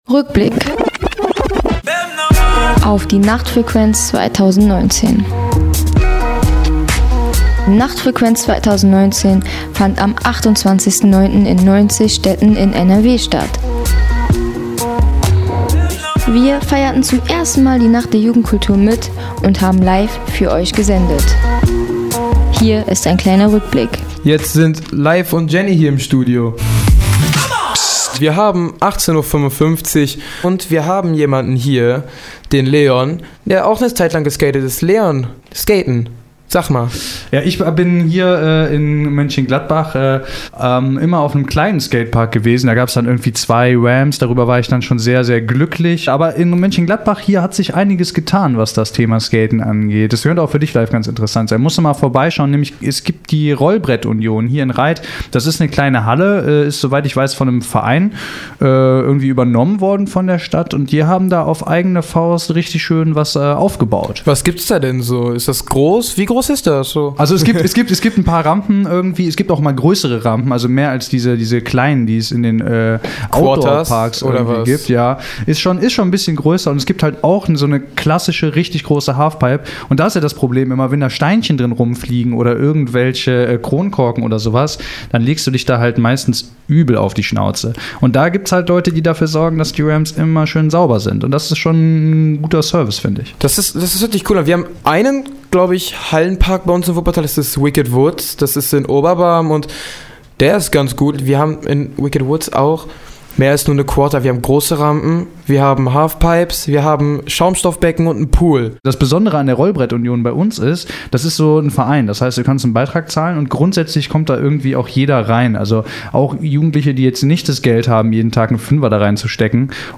Für Euch haben wir jetzt die besten Ausschnitte unserer Live-Sendung während dieser „langen Nacht der Jugendkultur“ herausgesucht.